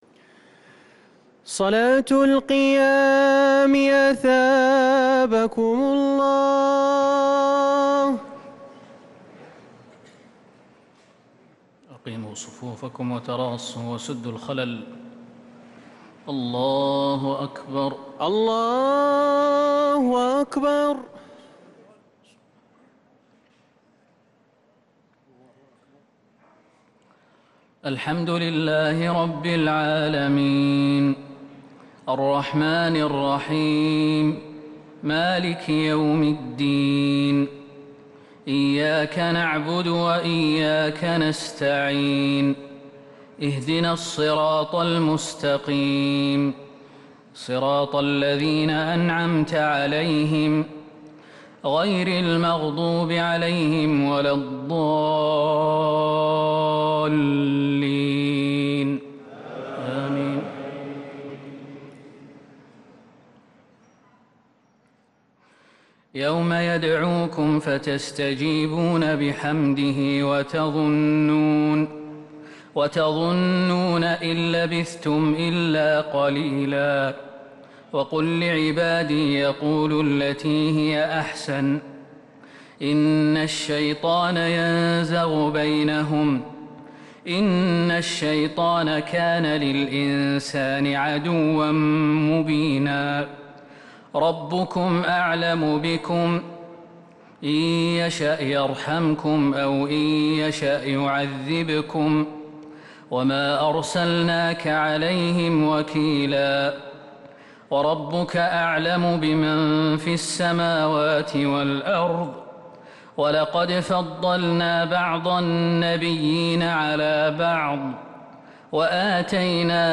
تراويح ليلة 20 رمضان 1443هـ من سورة الإسراء (52-اخرها) سورة الكهف (1-26) | Taraweeh 20st night Ramadan 1443H -Surah Al-Isra & Surah Al-Kahf > تراويح الحرم النبوي عام 1443 🕌 > التراويح - تلاوات الحرمين